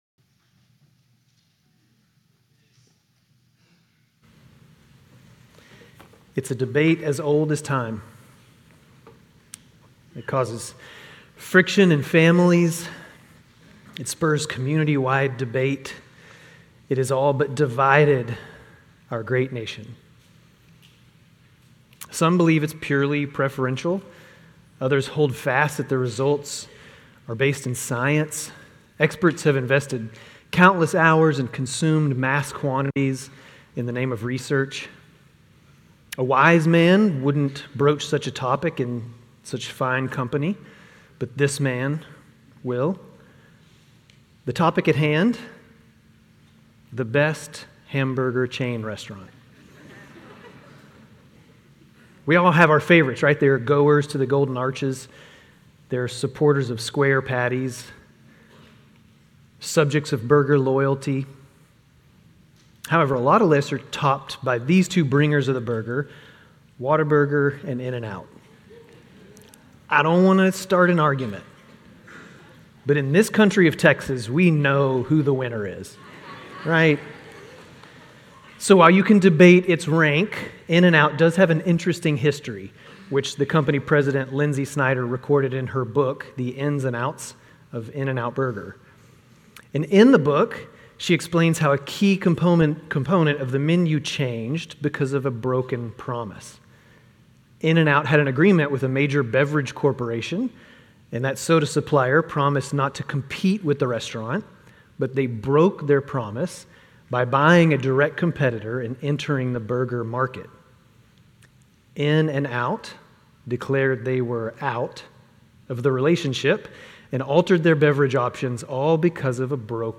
Grace Community Church Lindale Campus Sermons Galatians 3:15-29, 4:1-7 The Law and the Promise May 06 2024 | 00:21:35 1x 00:00 / 00:21:35 Subscribe Share RSS Feed Share Link Embed